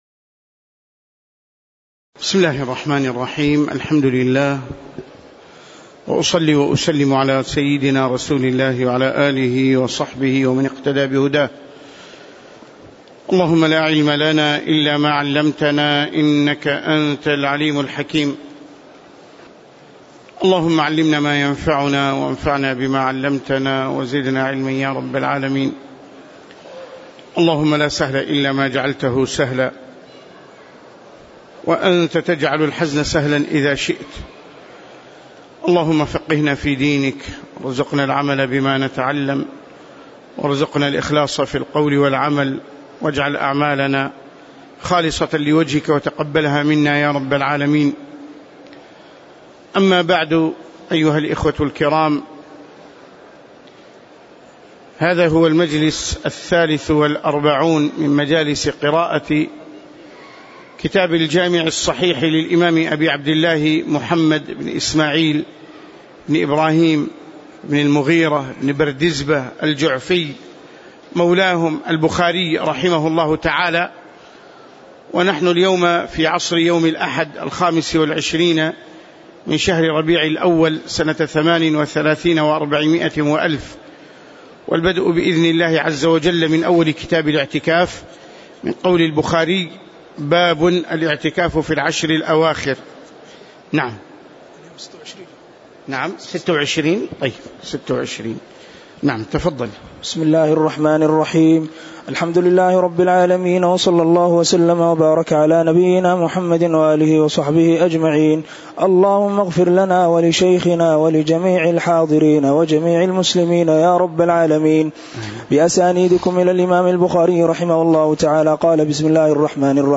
تاريخ النشر ٢٦ رمضان ١٤٣٨ هـ المكان: المسجد النبوي الشيخ